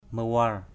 /mə-wa:r/ (đg.) thay thế, thay phiên = remplacer = to replace; alternate. tok mawar _tK mwR nhận lượt = prendre son tour de rôle = take turn. mawar gep khik...
mawar.mp3